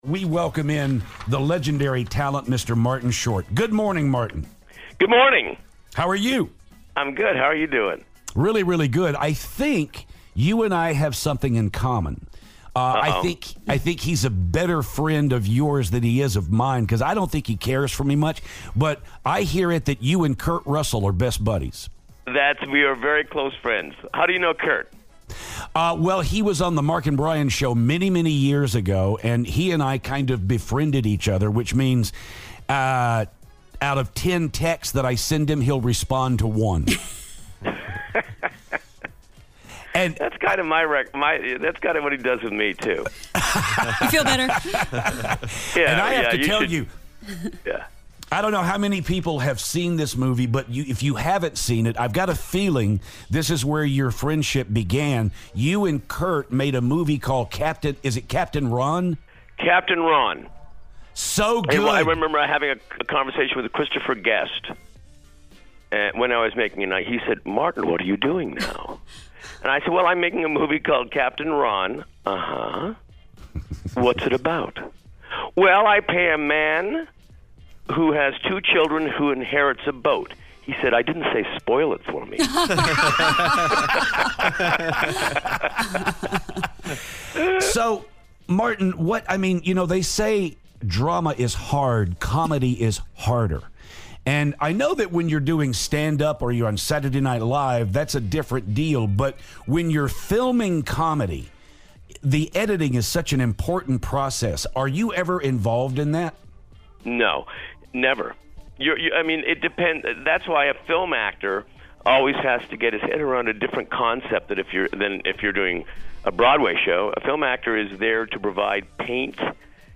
Martin Short Phoner